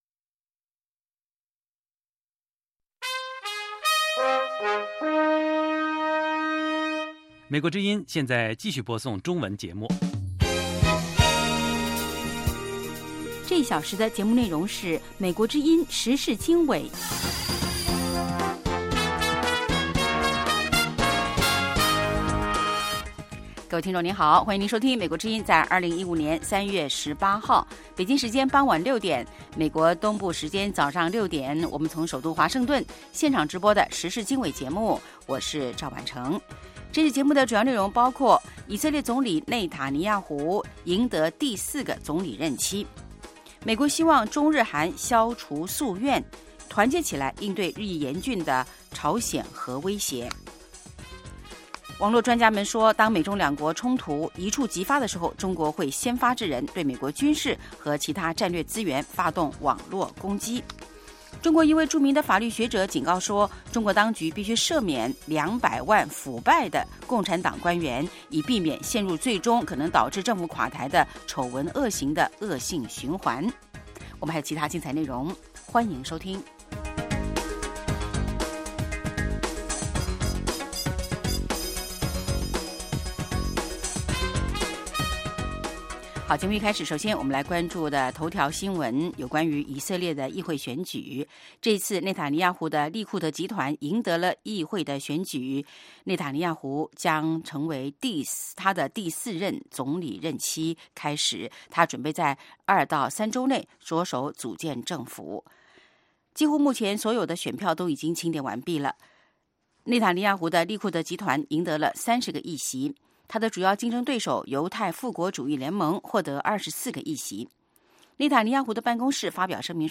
北京时间晚上6-7点广播节目